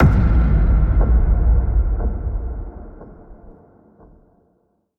We have had quite a few requests to make the sound of Renoirs Cane downloadable... so here you go: